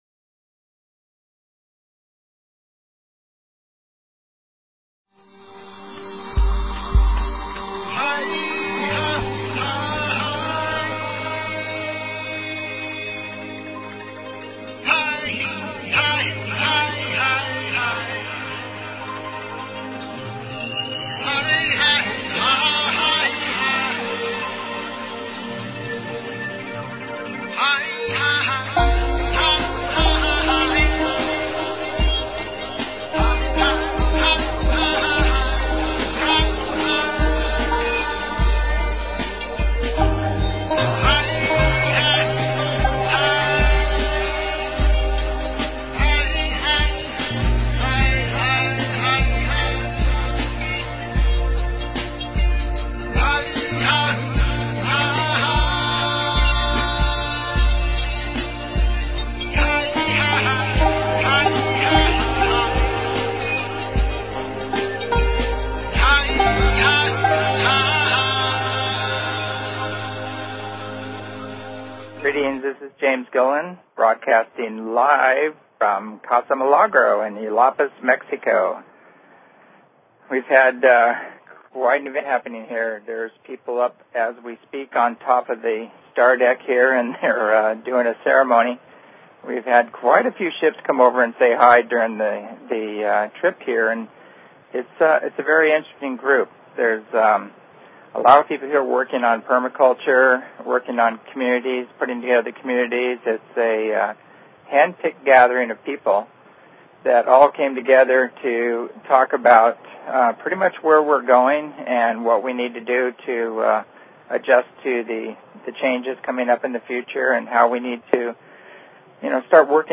Talk Show Episode, Audio Podcast, As_You_Wish_Talk_Radio and Courtesy of BBS Radio on , show guests , about , categorized as
Broadcast Live from Yelapa Mexico, Intentional Community Gathering